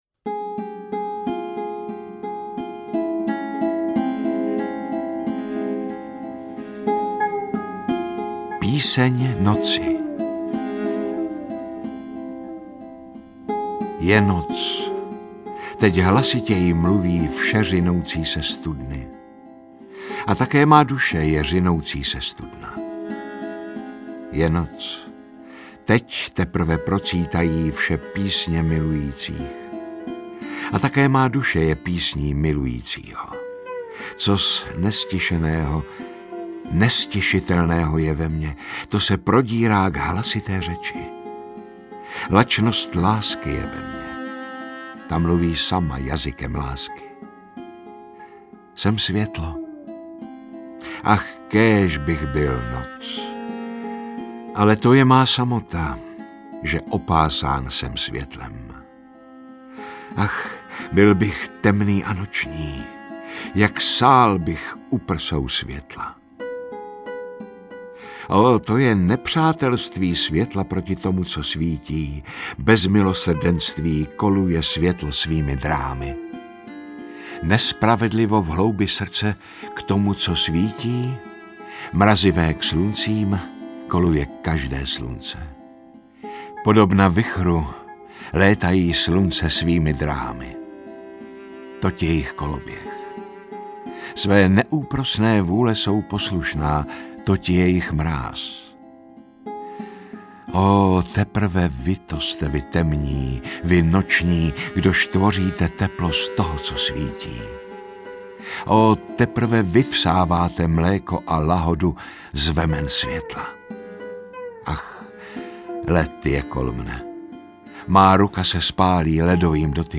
Tak pravil Zarathustra audiokniha
Ukázka z knihy